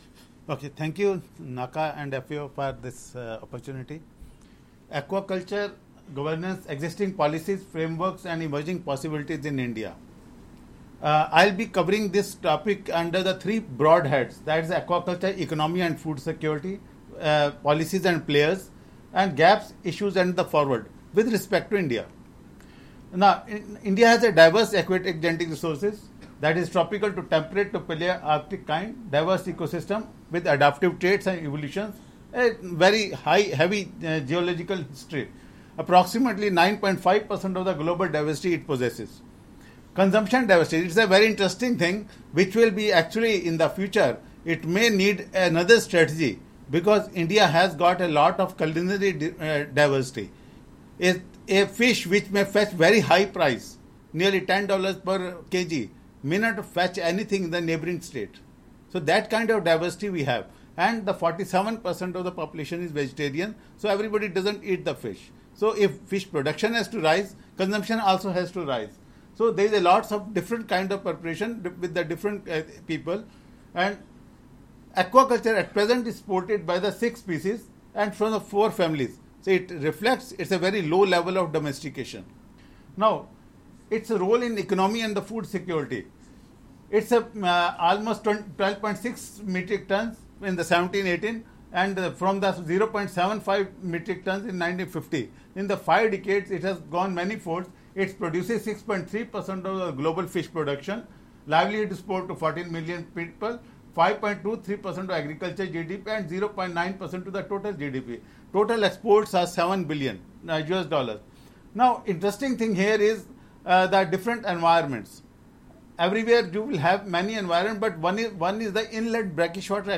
Audio recording of presentation delivered at the Consultation on Strengthening Governance of Aquaculture for Sustainable Development in Asia-Pacific, 5-6 November 2019, Bangkok, Thailand.